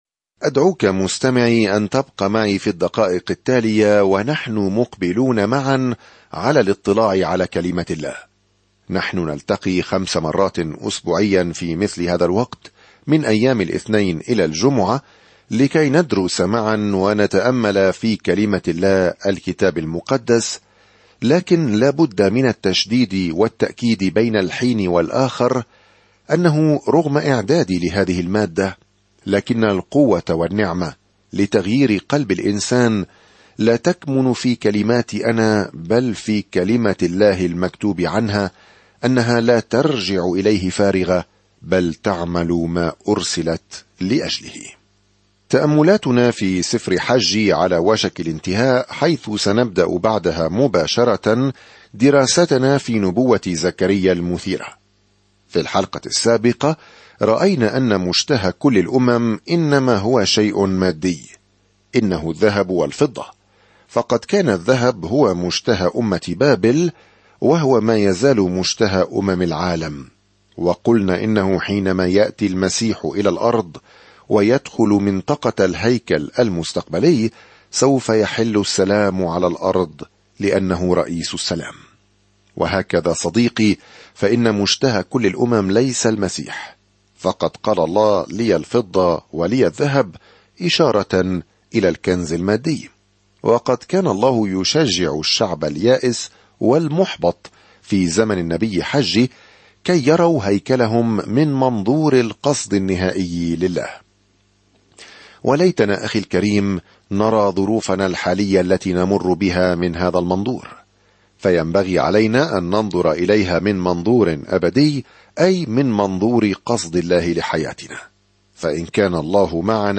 الكلمة حَجَّي 10:2-13 يوم 7 ابدأ هذه الخطة يوم 9 عن هذه الخطة إن موقف حجي "أنجز الأمر" يحث إسرائيل المشتتة على إعادة بناء الهيكل بعد عودتهم من السبي. سافر يوميًا عبر حجي وأنت تستمع إلى الدراسة الصوتية وتقرأ آيات مختارة من كلمة الله.